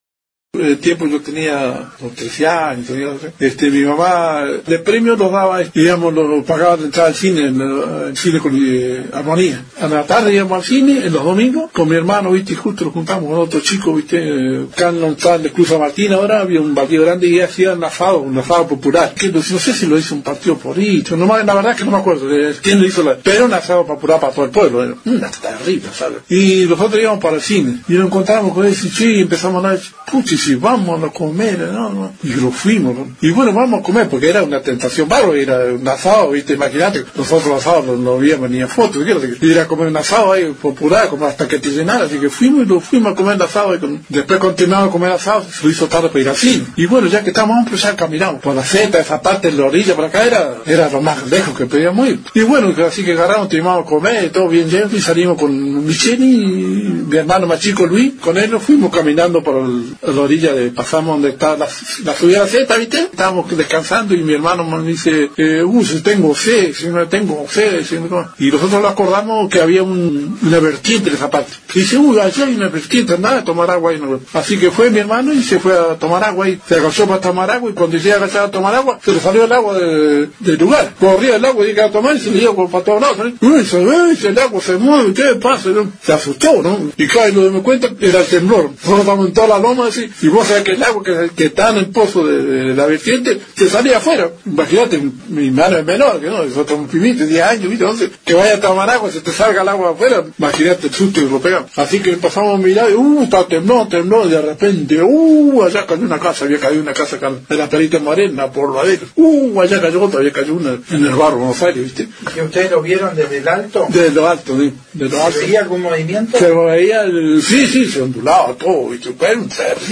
Ellos no sabían que pasaba en el momento que quisieron tomar agua de una vertiente y el agua se movía de un lado para el otro. Desde las alturas pudieron ver las ondulaciones del terreno y como se cayeron varias casas. Escuchá su relato.